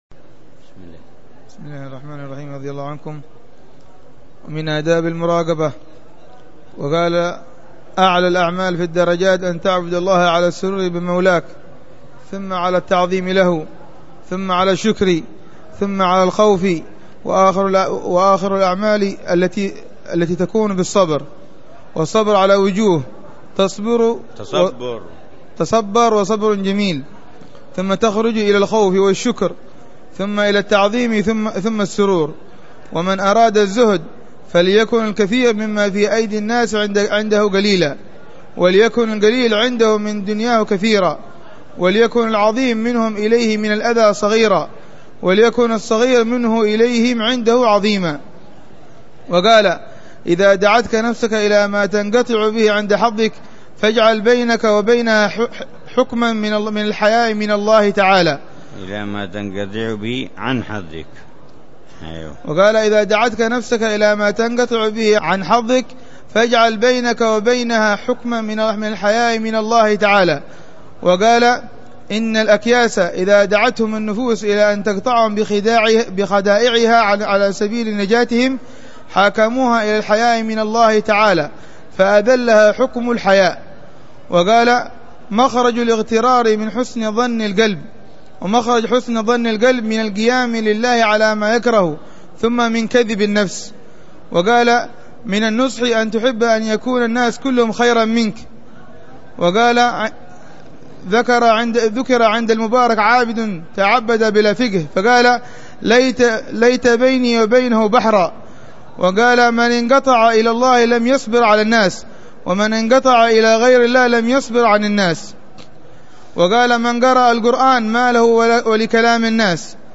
الدرس التاسع من دروس الحبيب عمر بن حفيظ في شرح كتاب آداب النفوس للإمام أبي عبد الله الحارث المحاسبي، يتحدث عن أهمية تهذيب النفس وتزكيتها والنه